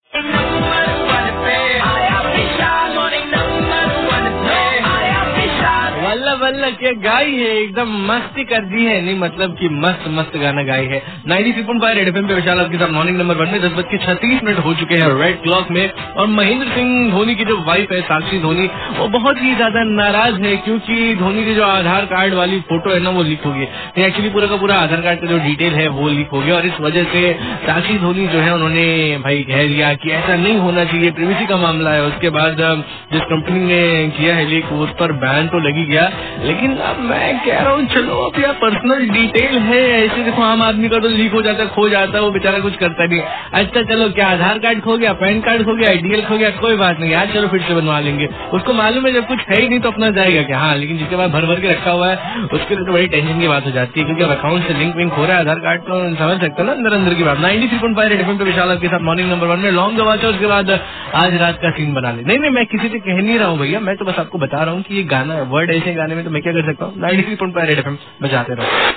rj about aadhar card